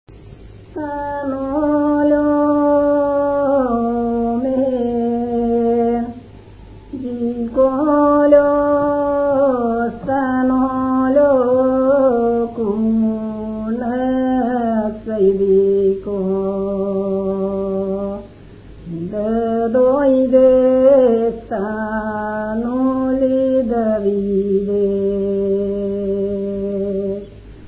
за българска фолклорна музика
музикална класификация Песен
размер Безмензурна
фактура Едногласна
начин на изпълнение Солово изпълнение на песен
място на записа Кърджали
начин на записване Магнетофонна лента